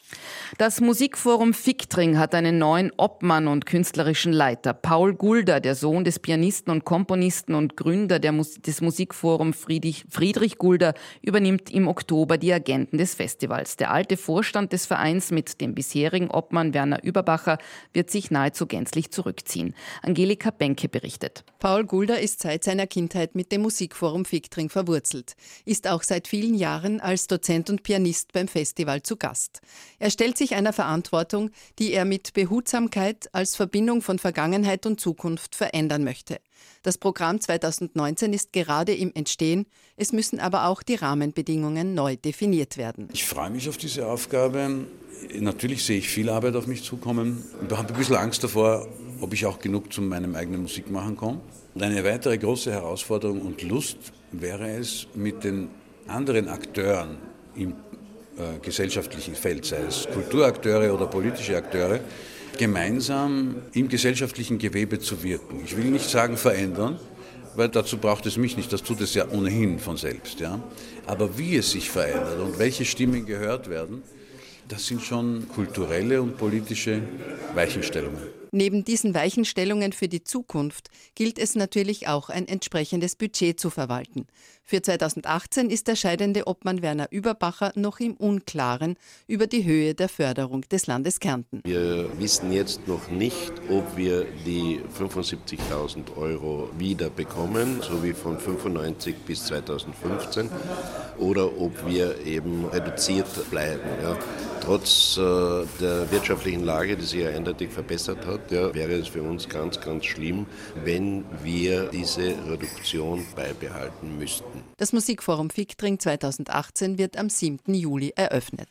Paul Gulda erweitert ebenfalls musikalische Räume.